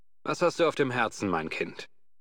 Fallout: Brotherhood of Steel: Audiodialoge
FOBOS-Dialog-Fremder_im_Ödland-001.ogg